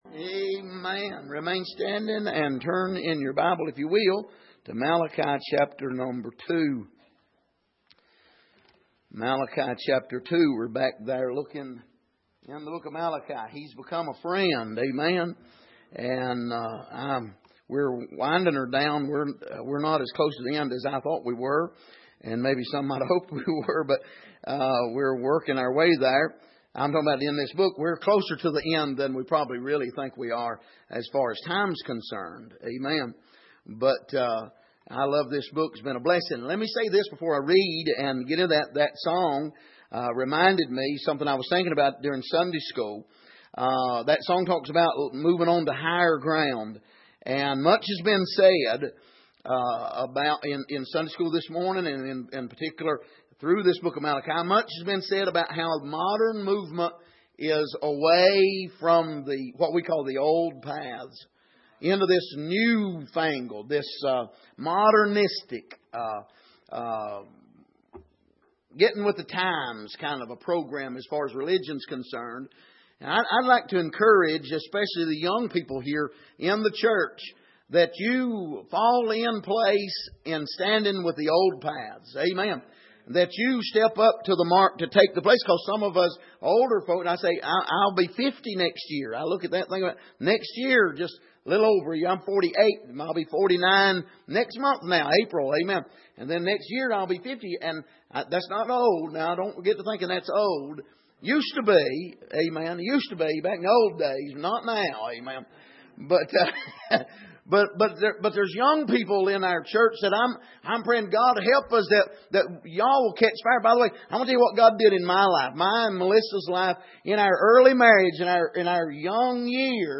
Malachi 2:17 Service: Sunday Morning Where Is The God of Judgment?